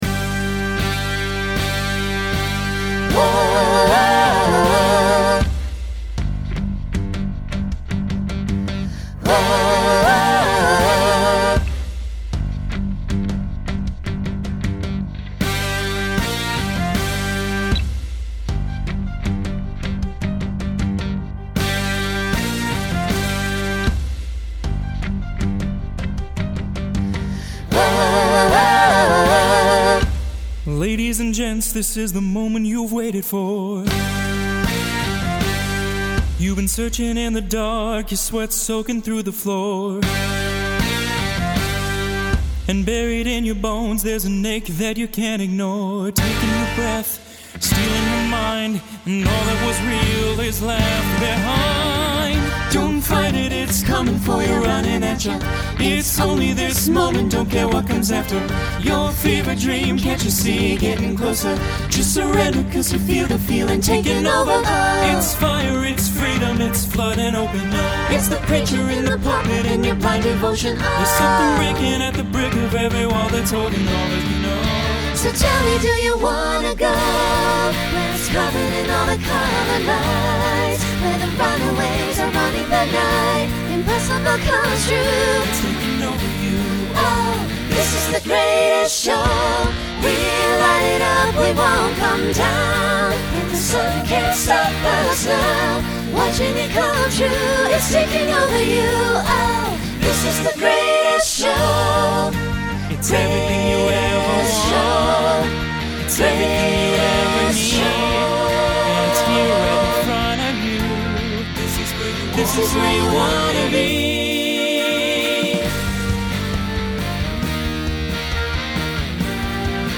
Genre Broadway/Film
Costume Change Voicing Mixed